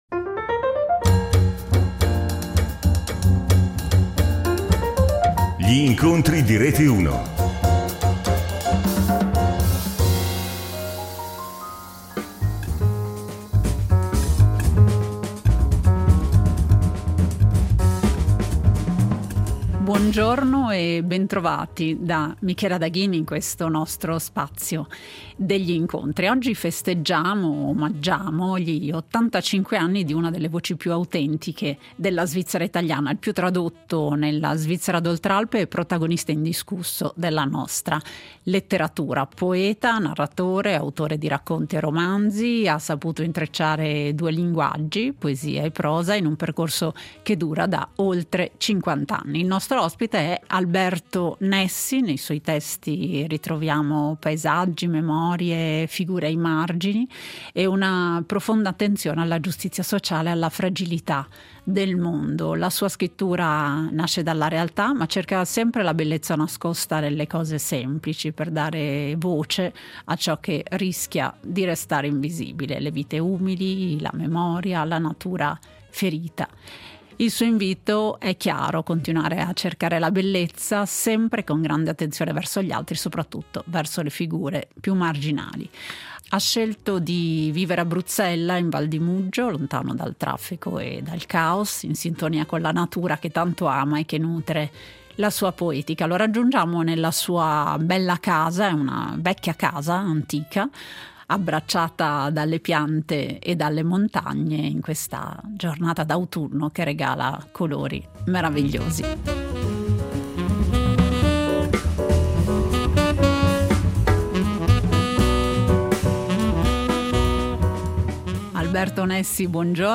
Lo abbiamo raggiunto nella sua casa antica, abbracciata dalle piante e dalle montagne, in una giornata d’autunno e di colori meravigliosi. Ripercorriamo insieme il suo cammino: la doppia natura di poeta e narratore, la necessità di condivisione con il lettore, la creatività che cresce con il tempo, il bisogno di solitudine, le mattinate rubate alle magistrali per andare a leggere in solitudine la grande letteratura o per osservare il mondo operaio nei bar di Locarno.